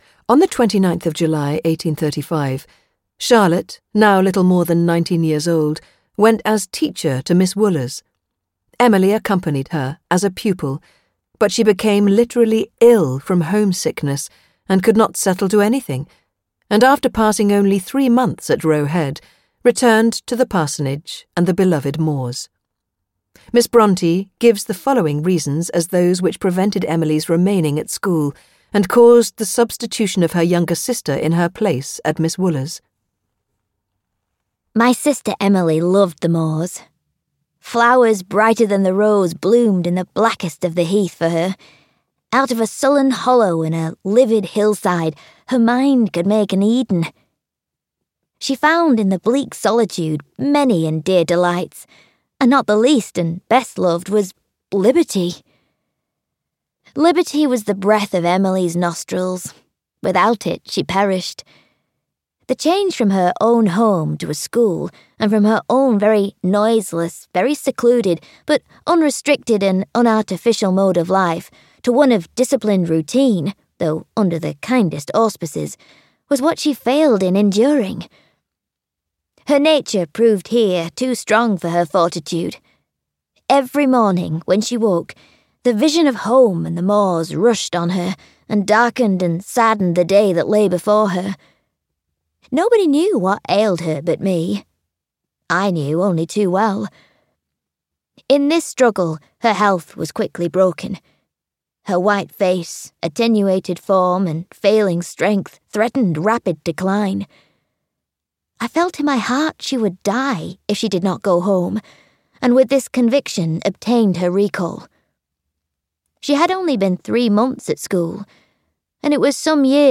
Ukázka z knihy
It springs to life here in a full cast recording.